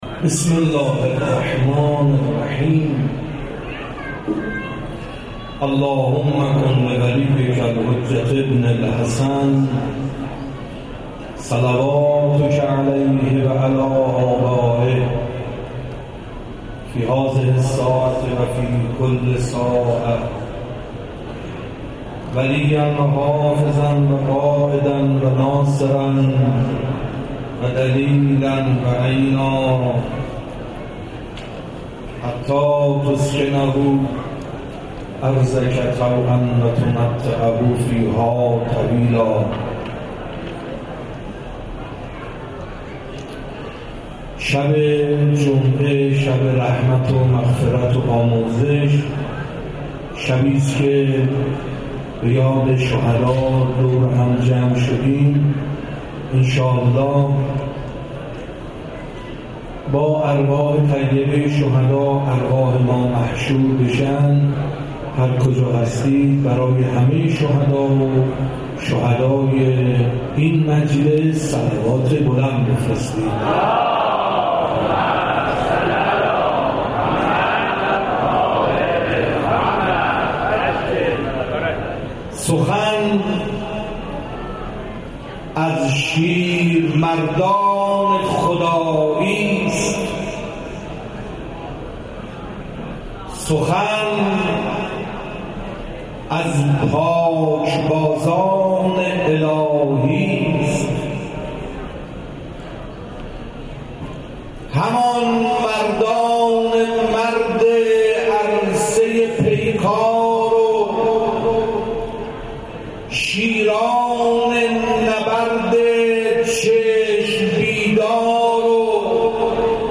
مداحی
در یادواره شهدای شهریار
در مسجد حضرت ولیعصر(عج) کرشته برگزار شد. در ادامه مراسم گروه همخوانی موسی بن جعفر (ع) به اجرای مراسم پرداخت.